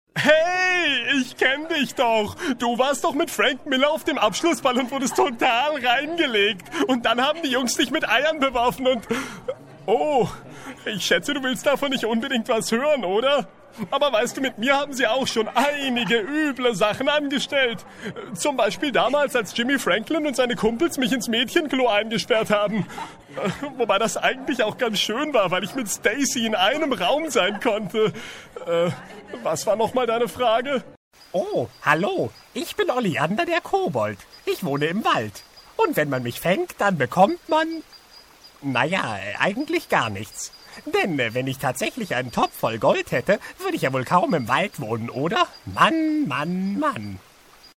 Sprechprobe: eLearning (Muttersprache):
german voice over talent, computer-gemes, audiobooks ...